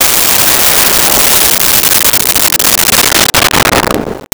Missle 09
Missle 09.wav